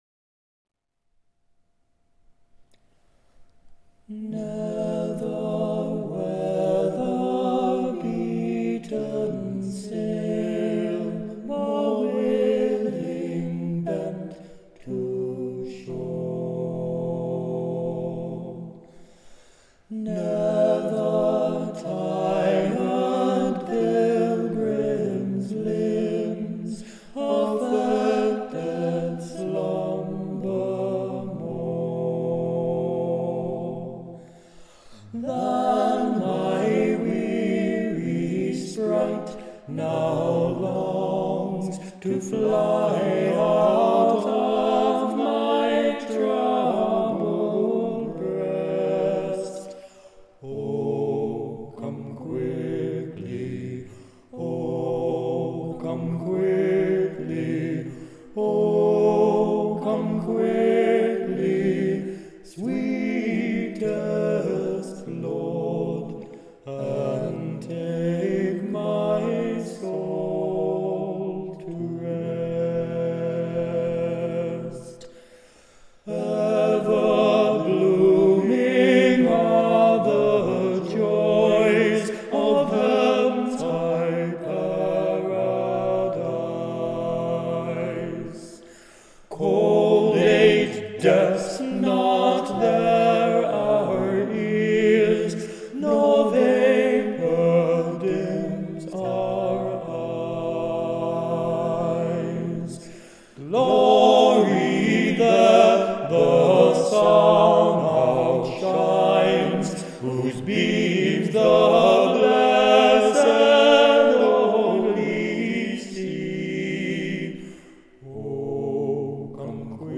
Singing with them over the next six years, he developed a love of harmony singing and unaccompanied vocals.
multi-tracked by him one dark still night on the island of Iona